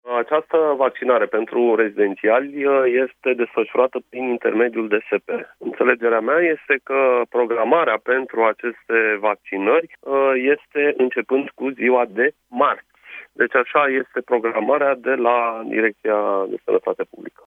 Primarul sectorului, Radu Mihaiu, explică: